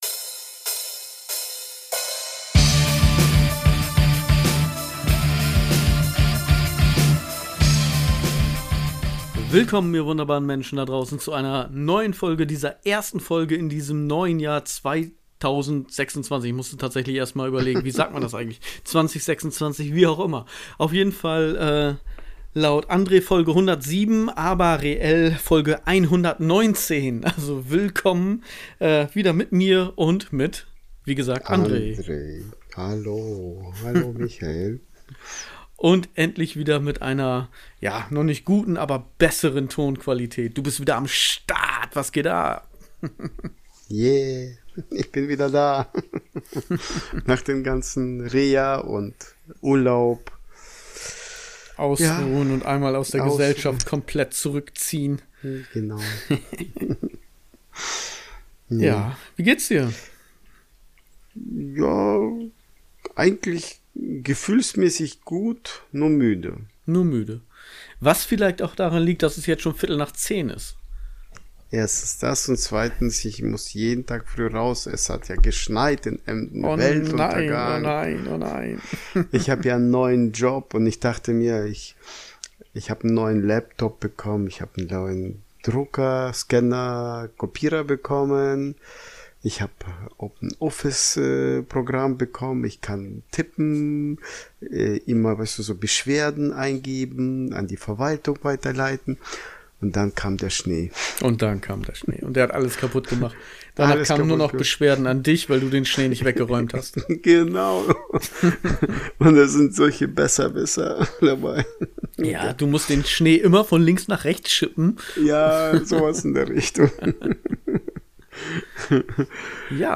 Genres: Comedy, Entertainment News, Improv, News
auch wenn der Ton seiner Spur nicht der burner ist